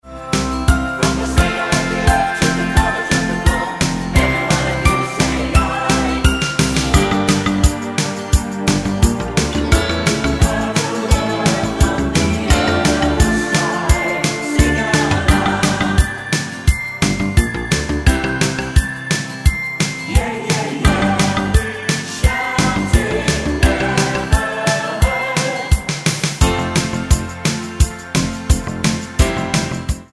Tonart:F mit Chor
Die besten Playbacks Instrumentals und Karaoke Versionen .